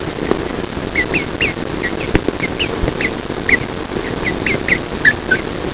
These are portraits of dearly departed chickens who lived with us; please enjoy some chicken noises recorded straight from the barn as you peruse!
Chicken Noises - Click to Listen One Nice Chick One Mad Chick